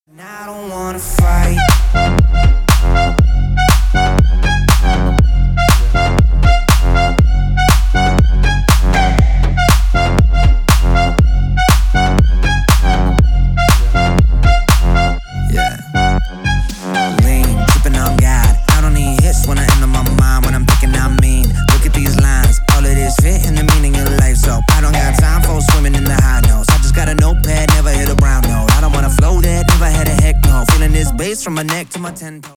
Рэп и Хип Хоп
клубные # громкие